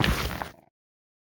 Minecraft Version Minecraft Version 1.21.5 Latest Release | Latest Snapshot 1.21.5 / assets / minecraft / sounds / block / netherrack / step3.ogg Compare With Compare With Latest Release | Latest Snapshot